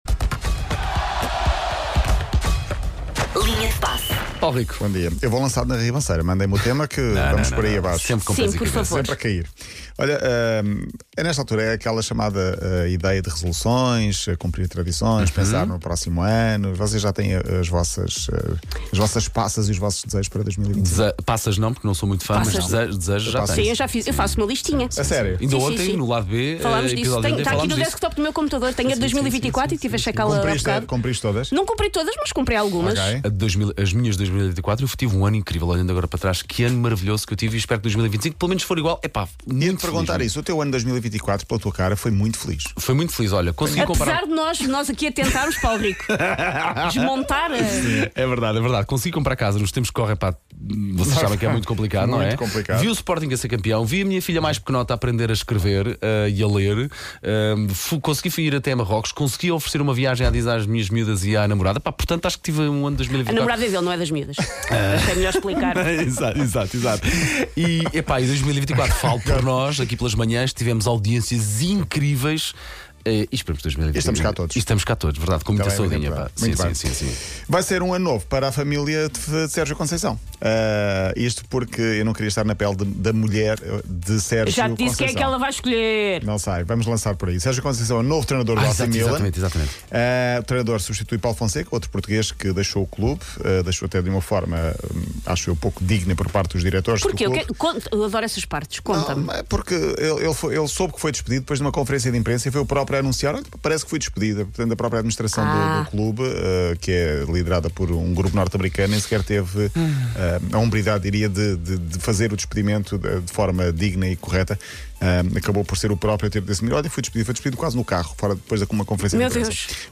E traz também uma entrevista com Pepe